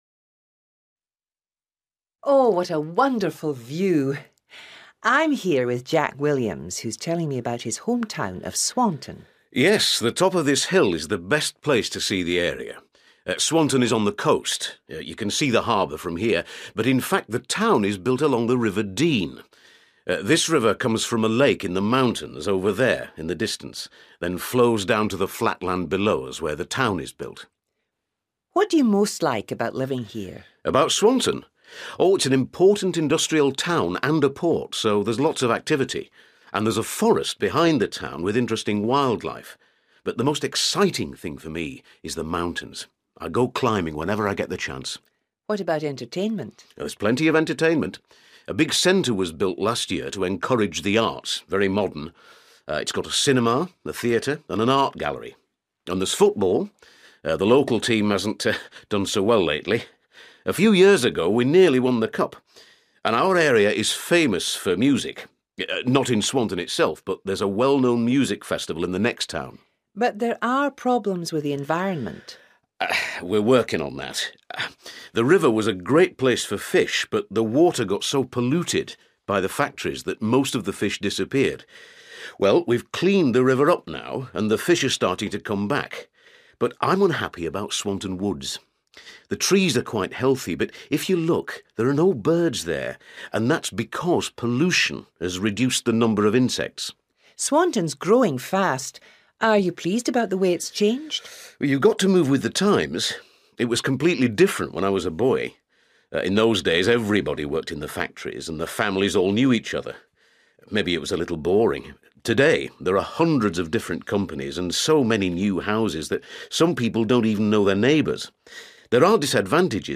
You will hear a radio interview